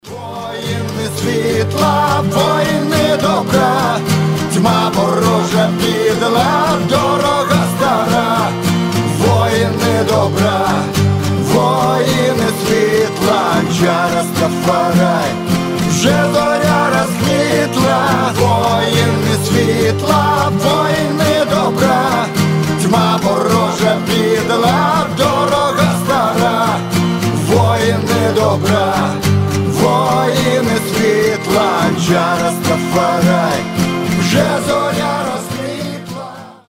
гитара
акустика
Версия белорусской песни на украинском